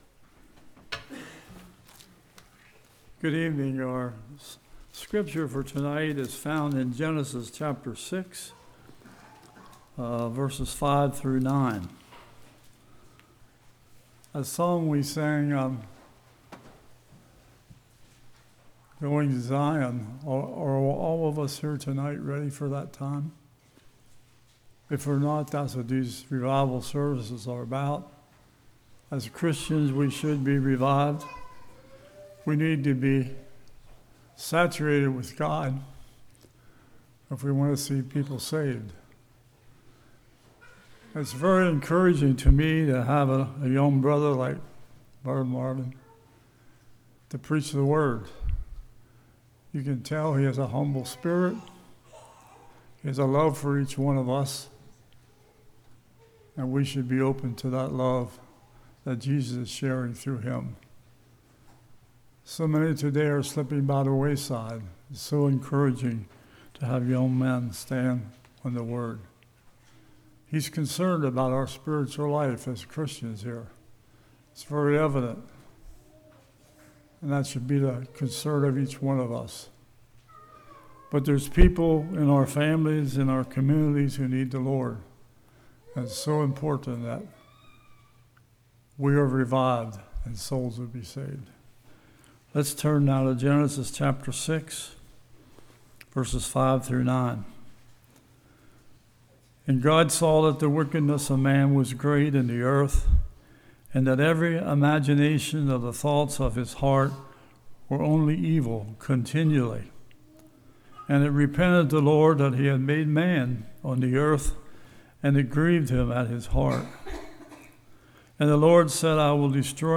Passage: Genesis 6:5-9 Service Type: Revival